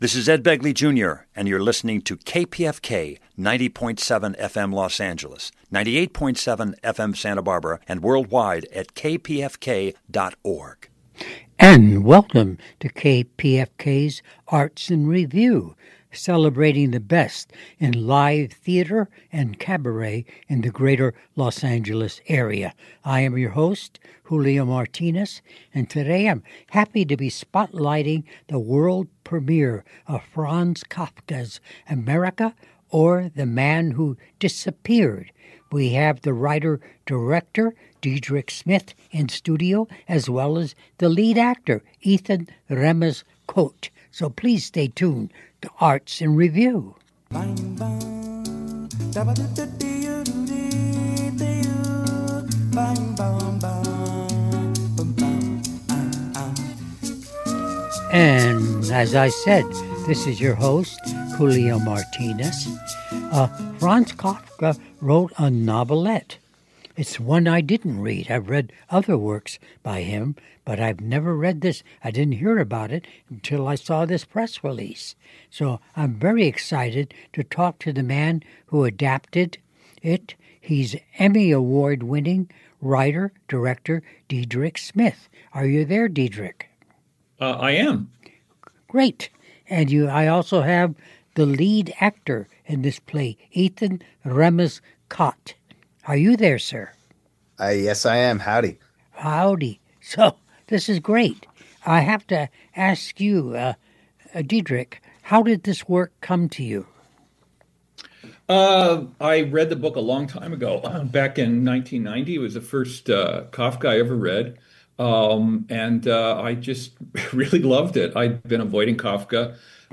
Amerika_KPFK90.7FM.mp3